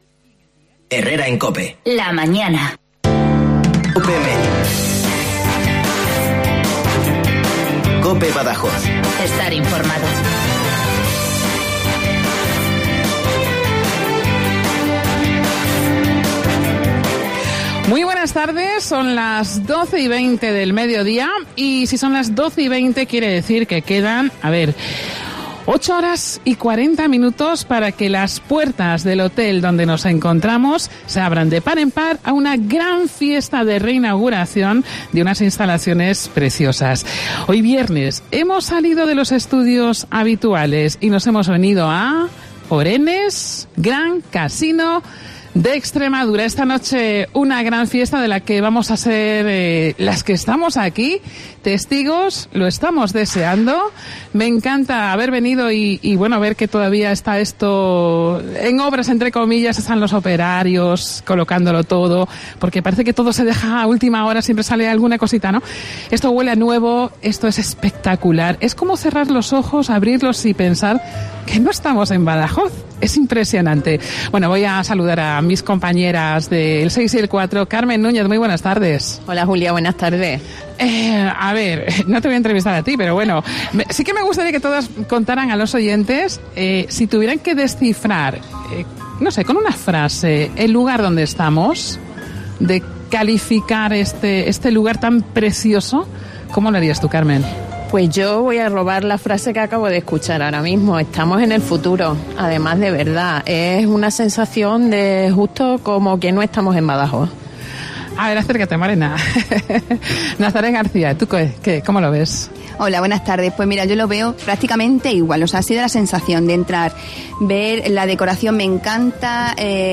En directo desde Orenes Gran Casino de Extremadura